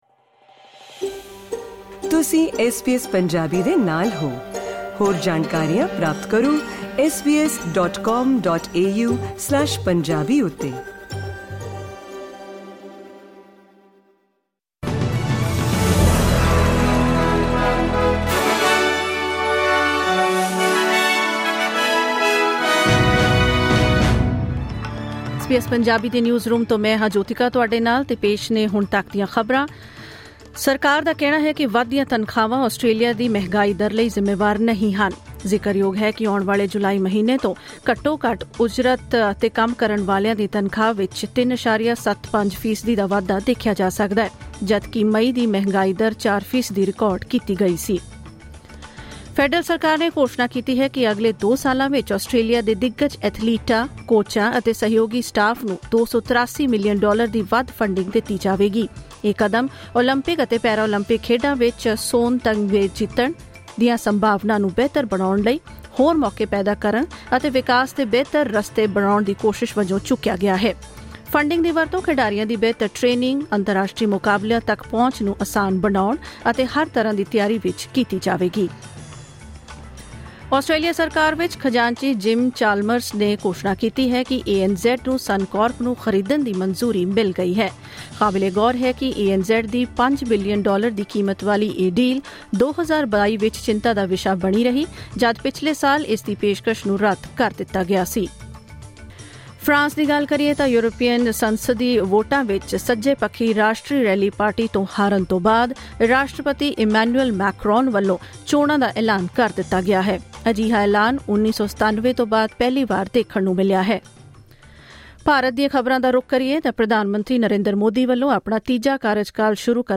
ਐਸ ਬੀ ਐਸ ਪੰਜਾਬੀ ਤੋਂ ਆਸਟ੍ਰੇਲੀਆ ਦੀਆਂ ਮੁੱਖ ਖ਼ਬਰਾਂ: 28 ਜੂਨ, 2024